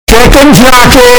Chicken Jockey Bass Boosted Sound Button: Meme Soundboard Unblocked
Chicken Jockey Bass Boosted